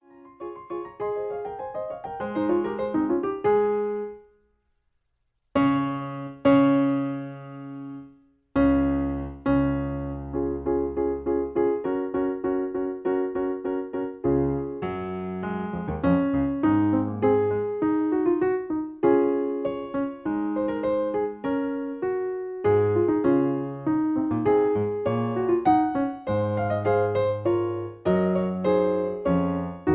In C sharp. Piano Accompaniment